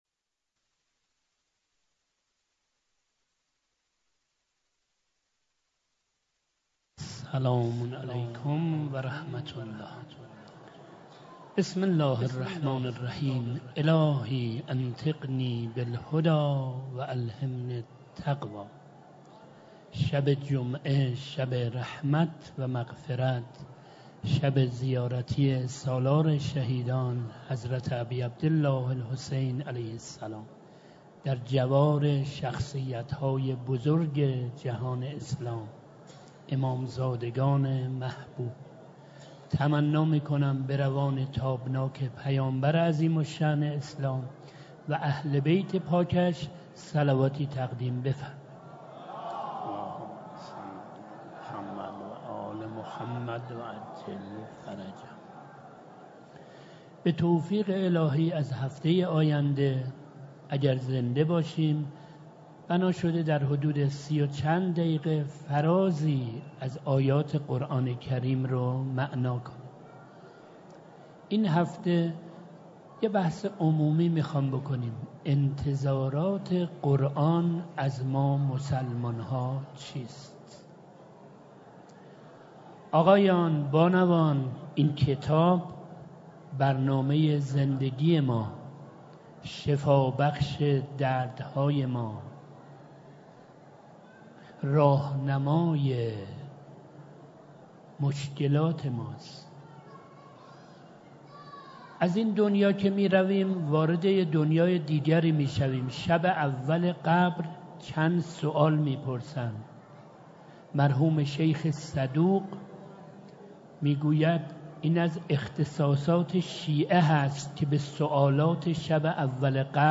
جلسه اول - تفسیر قرآن